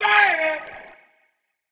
Vox (Dayum).wav